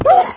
minecraft / sounds / mob / wolf / hurt1.ogg
hurt1.ogg